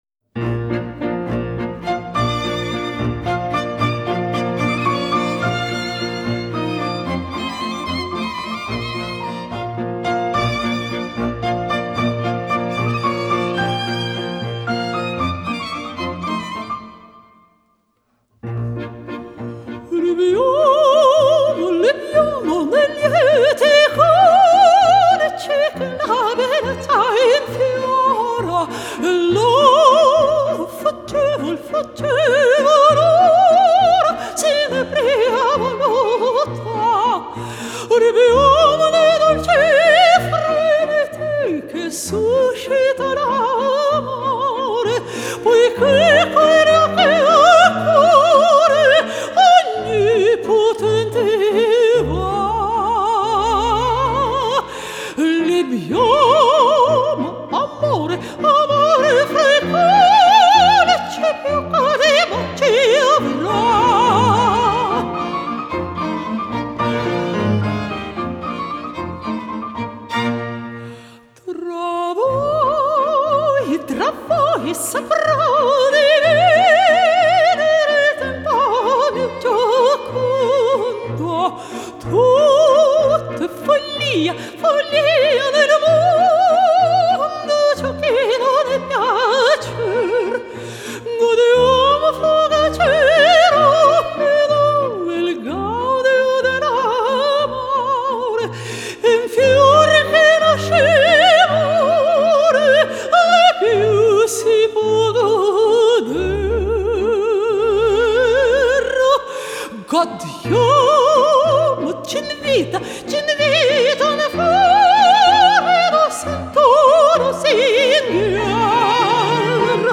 клвссика